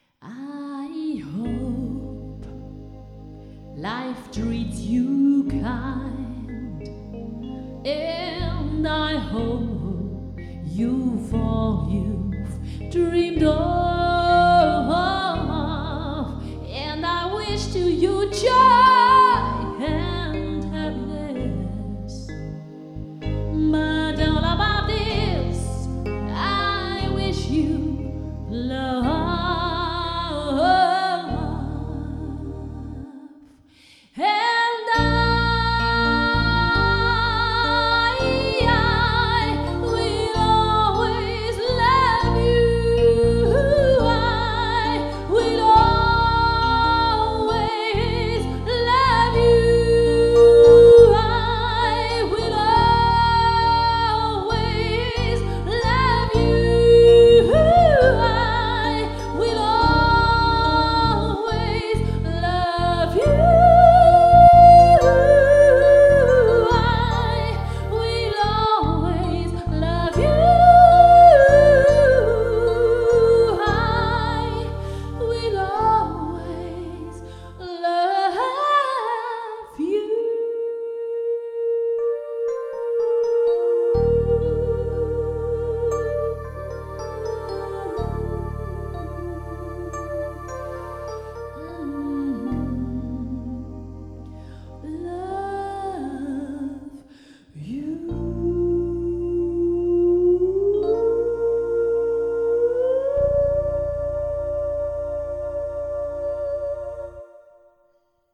(für die Kirche)